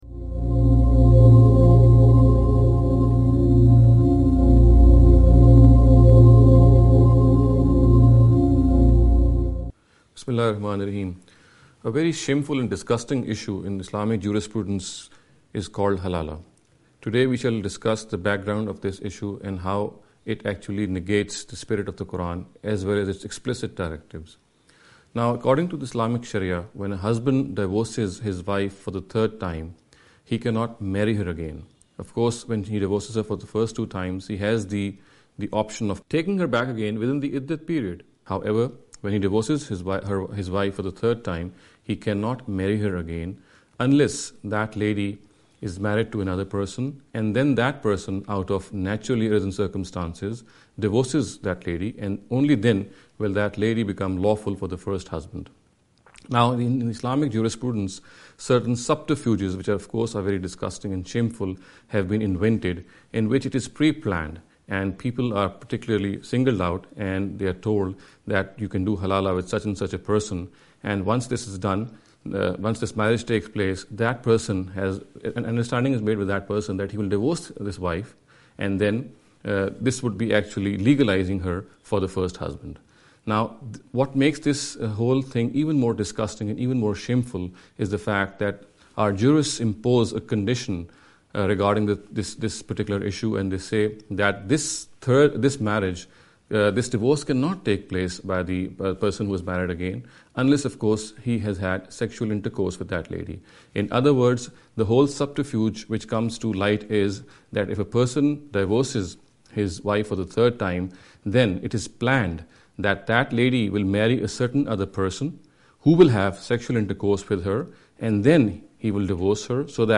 This lecture series will deal with some misconception regarding the Islam & Women.